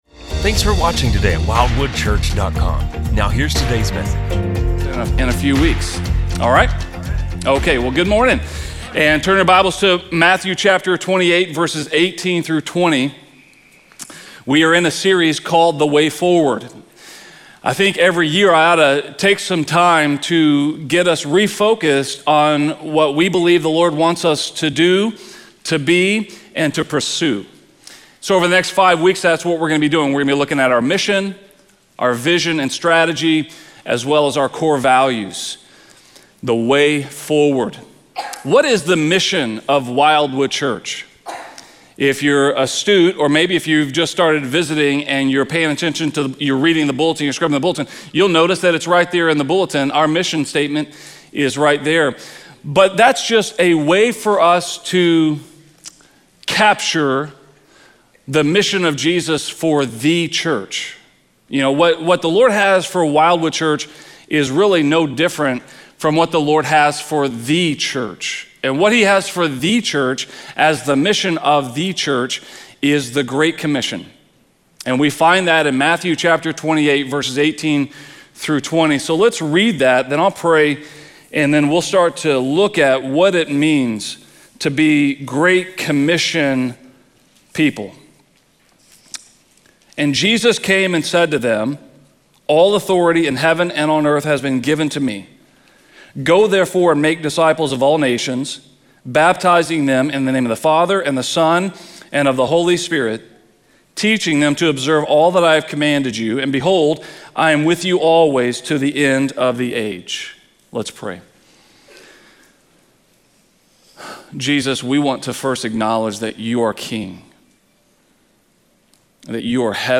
A message from the series "The Way Forward." Families have huge impact on our communities.